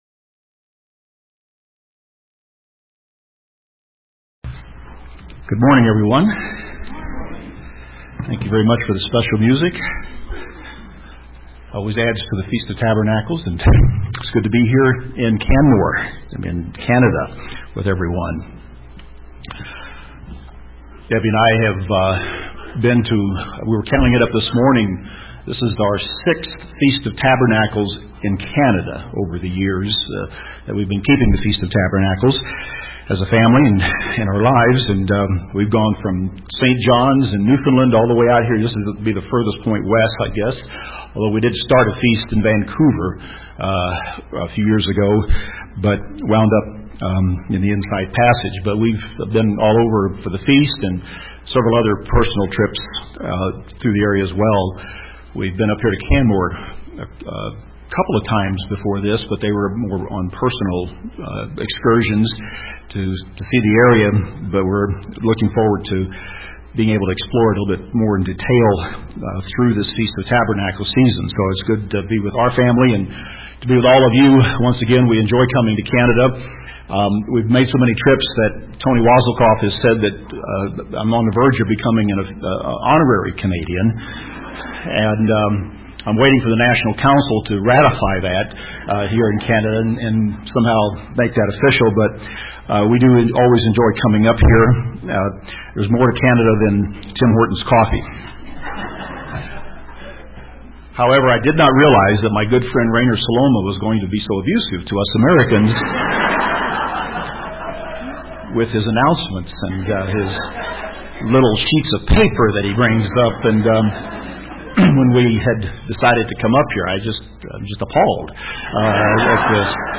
This sermon was given at the Canmore, Alberta 2015 Feast site.